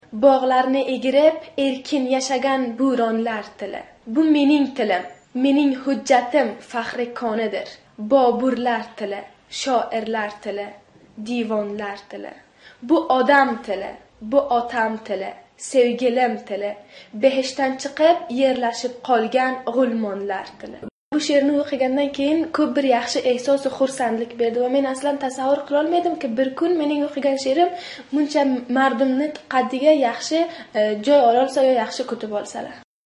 The timbre of the voice of the woman on that sounded almost exactly the same as in the quiz.